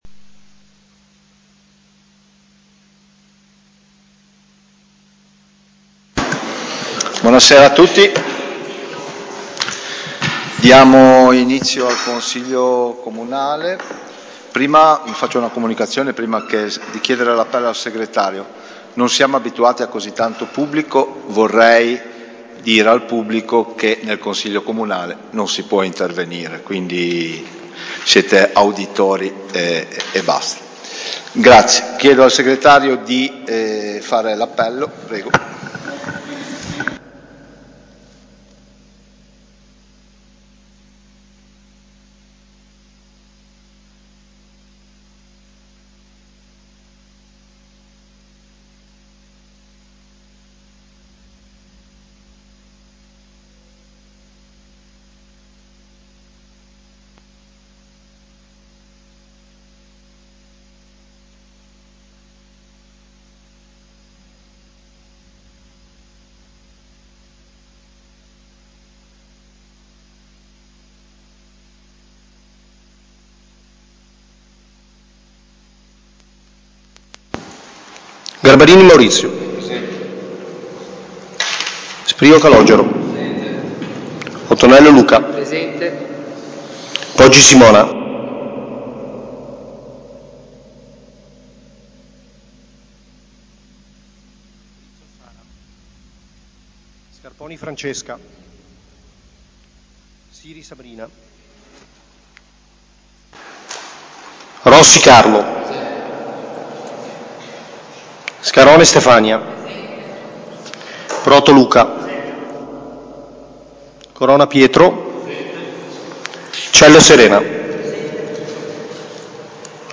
Seduta del Consiglio comunale martedì 10 marzo 2026, alle 21.00, presso l'Auditorium comunale in via alla Massa.